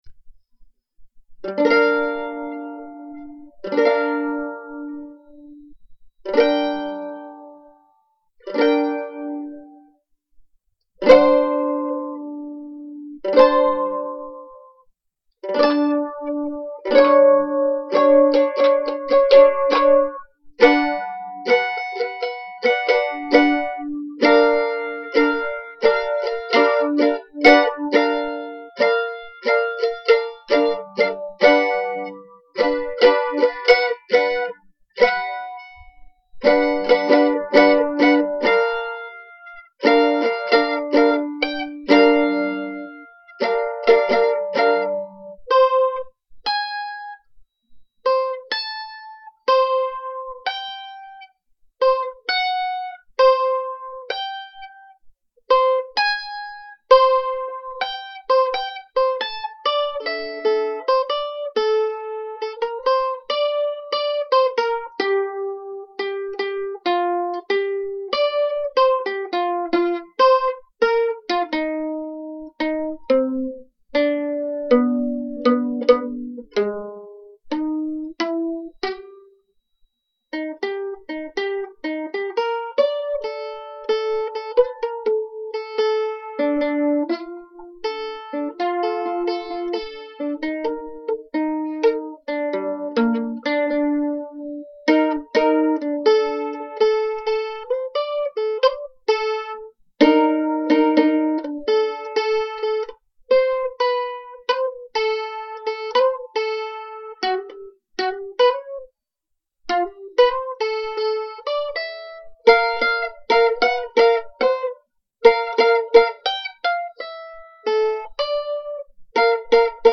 Tags: mandolin jam music